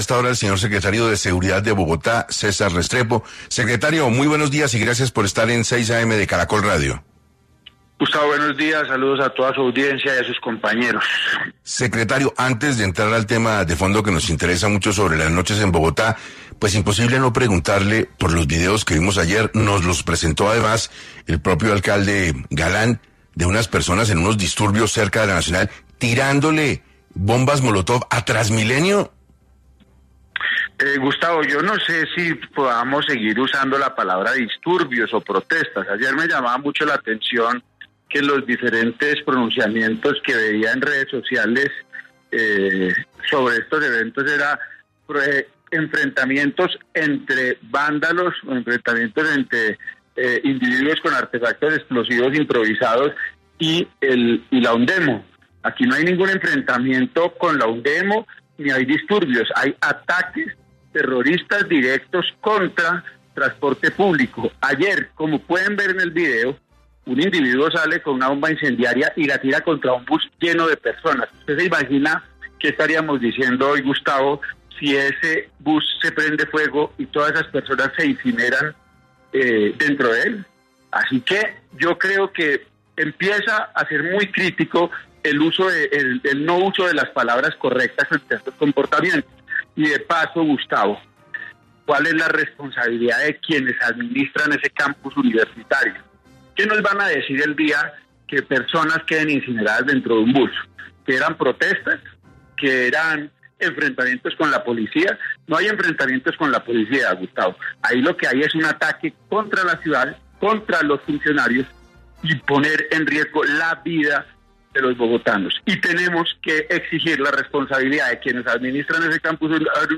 César Restrepo, secretario de Seguridad de Bogotá, explicó en 6AM la solicitud de la Alcaldía para intervenir bares que se hacen pasar por “sindicatos” con el fin de evadir las restricciones de horario